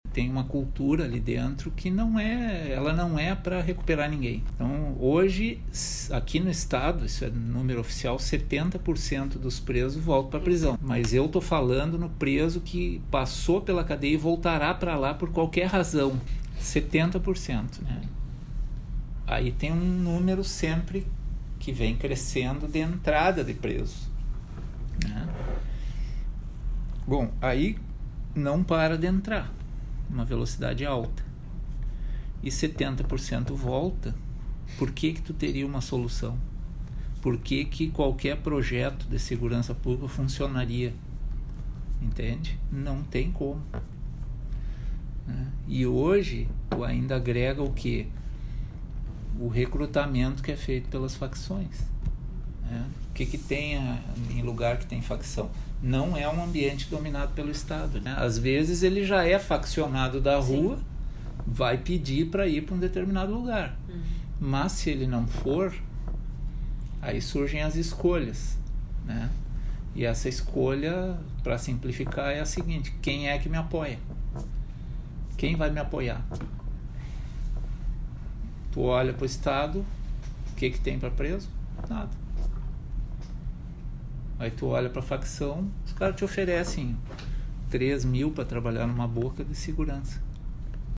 Gilmar Bortolotto, procurador de Justiça do Ministério Público do Rio Grande do Sul, explica como acontece a cooptação